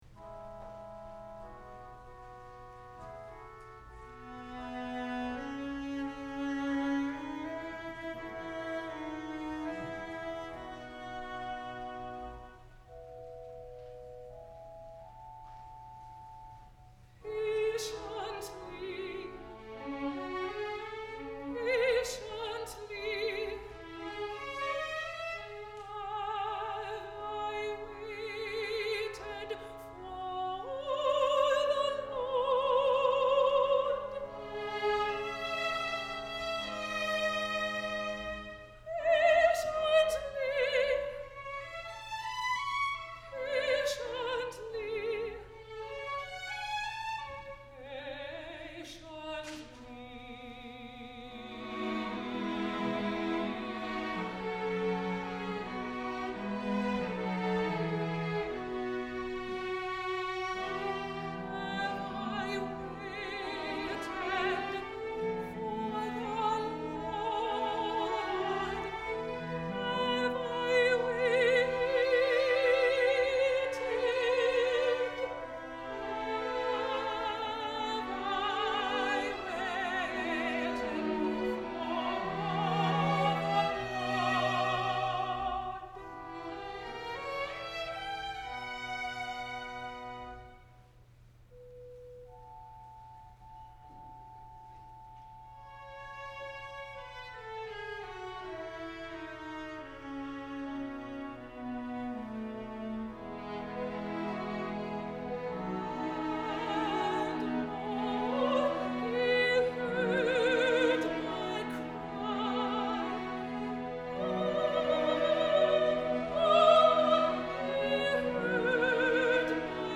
No. 3 Air
The Christmas Oratorio by Camille Saint–Saëns (1835–1921) was written in 1858, and was originally scored for soloists, chorus, harp, organ and string quartet.
alto, is an Ohio native and graduate of The Ohio State University’s graduate vocal performance program.